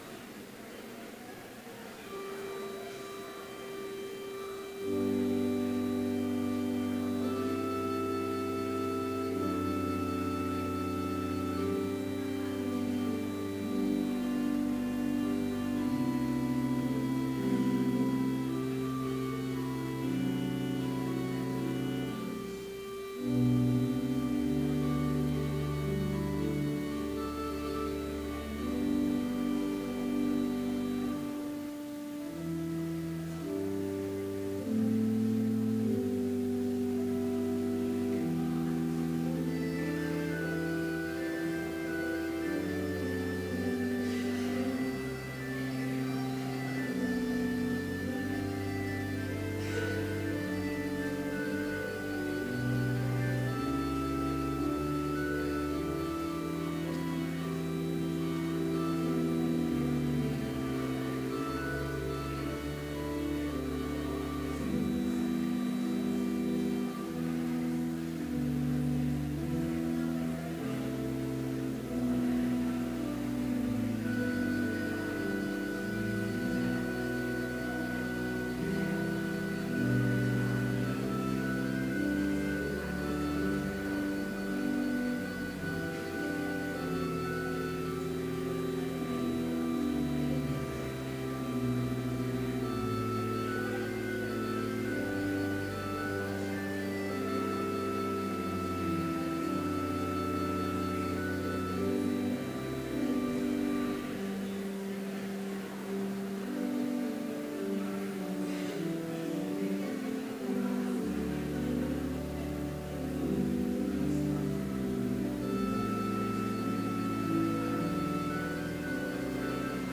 Complete service audio for Chapel - May 2, 2016